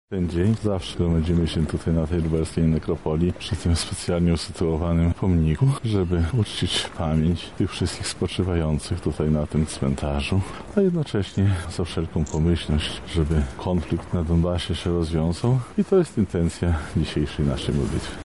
Mówił Prawosławny Arcybiskup Lubelski i Chełmski Abel.